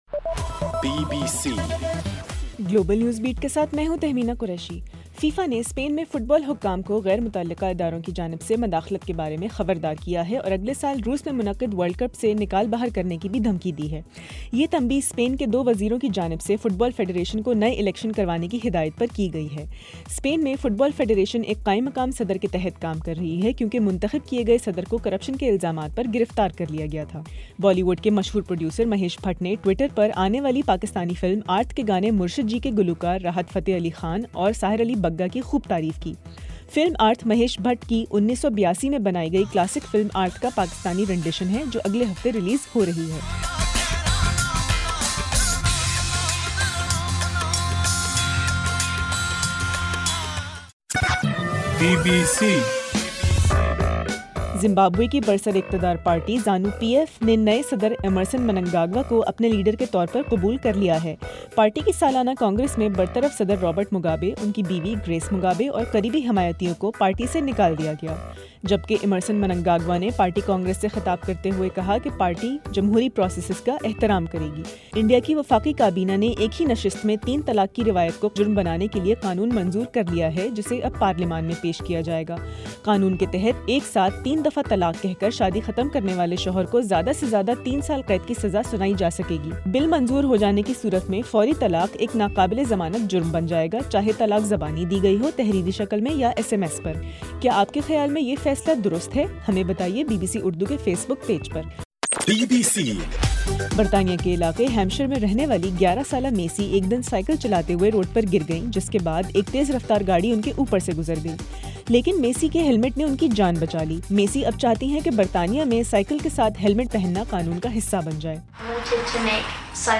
گلوبل نیوز بیٹ بُلیٹن اُردو زبان میں رات 8 بجے سے صبح 1 بجے تک ہر گھنٹےکے بعد اپنا اور آواز ایف ایم ریڈیو سٹیشن کے علاوہ ٹوئٹر، فیس بُک اور آڈیو بوم پر ضرور سنیے۔